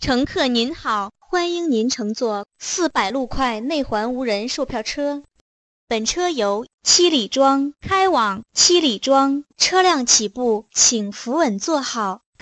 Professional AI Bus Announcement Voice for Public Transit
Clear AI Bus Announcement Voice
Deploy a distinct, intelligible AI voice engineered for public transportation systems, ensuring every stop and safety alert is heard clearly.
Text-to-Speech
Our AI voice is engineered with specific frequency adjustments to ensure maximum intelligibility over public address (PA) systems.